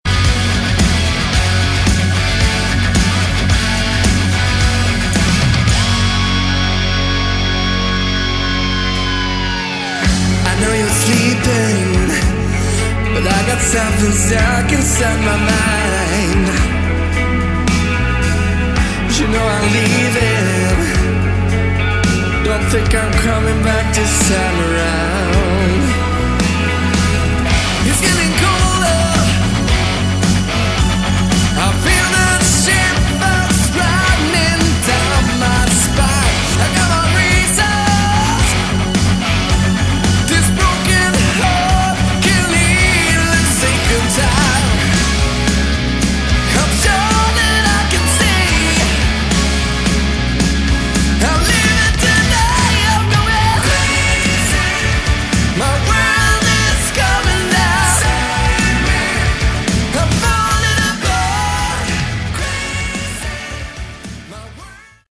Category: Hard Rock
guitars
drums
vocals
bass
keyboards